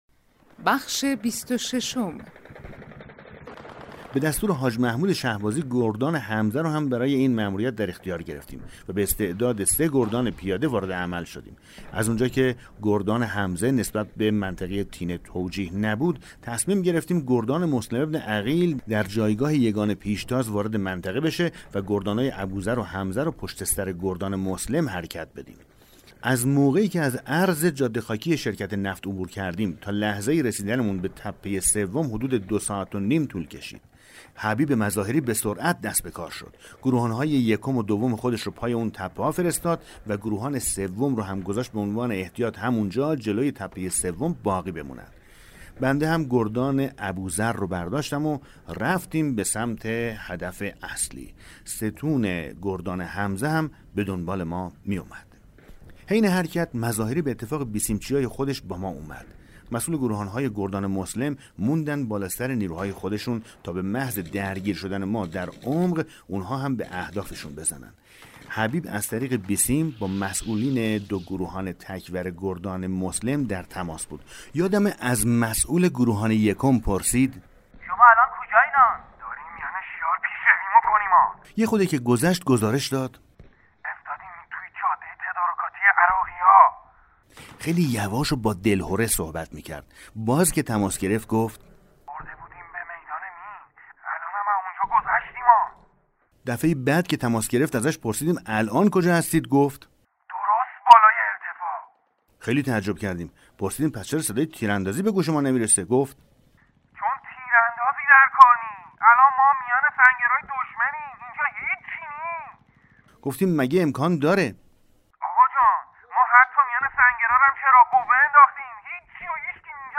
کتاب صوتی پیغام ماهی ها، سرگذشت جنگ‌های نامتقارن حاج حسین همدانی /قسمت 26